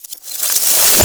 casting_charge_matter_grow_03.wav